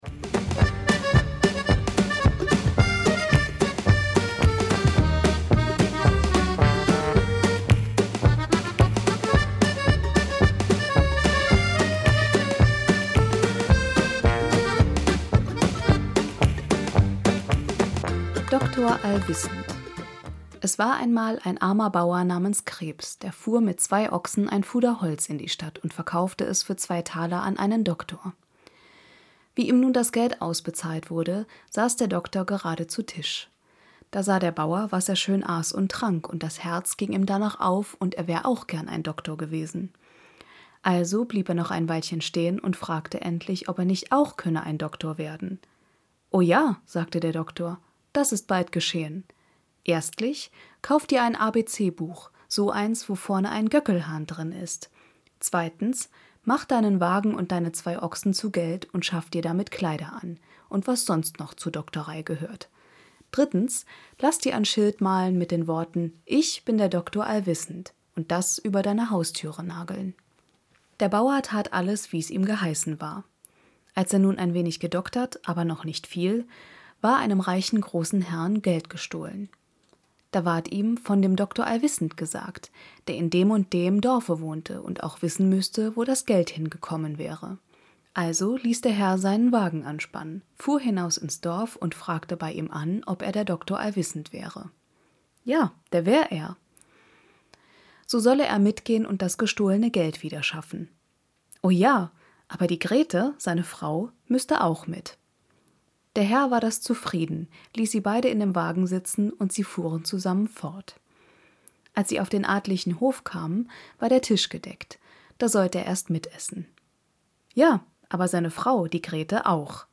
Music by Tunetank from Pixabay